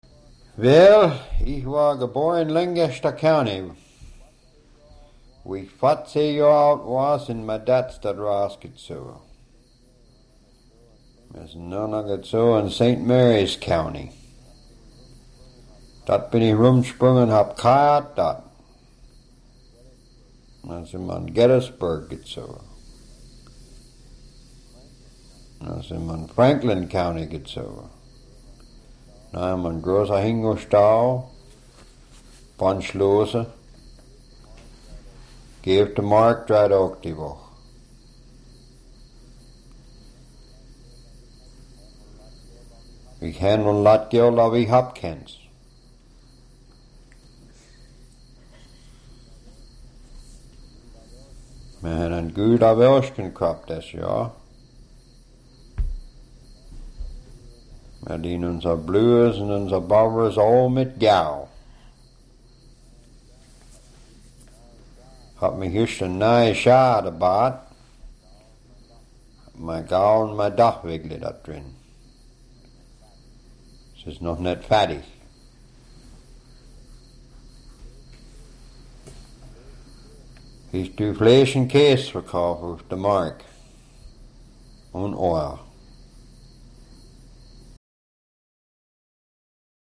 features an Amish man being interviewed
in Franklin County, Pennsylvania, USA in 1984.